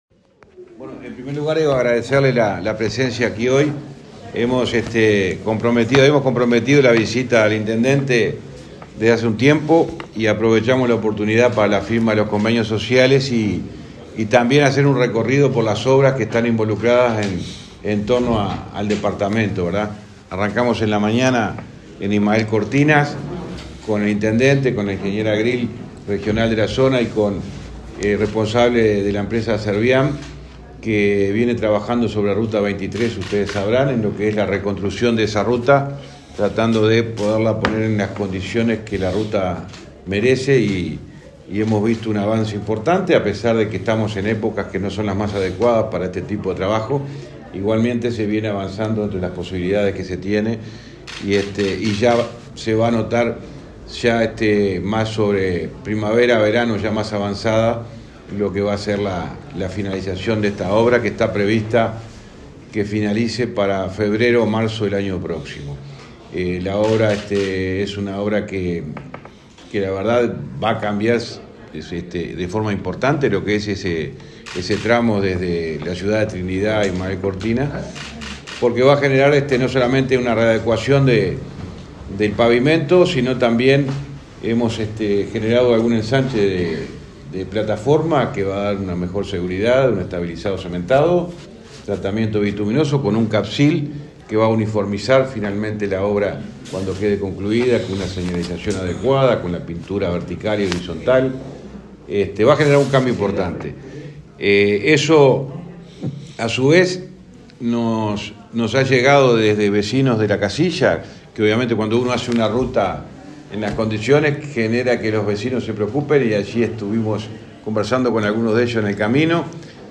Conferencia de prensa del ministro de Transporte, José Luis Falero
Conferencia de prensa del ministro de Transporte, José Luis Falero 22/09/2023 Compartir Facebook X Copiar enlace WhatsApp LinkedIn El ministro de Transporte, José Luis Falero, brindó una conferencia de prensa en la ciudad de Trinidad, Flores, donde firmó varios convenios con instituciones sociales.